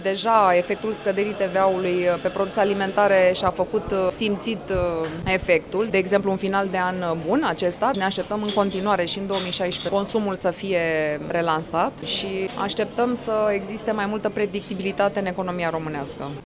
PR manager-ul unui hipermarket din România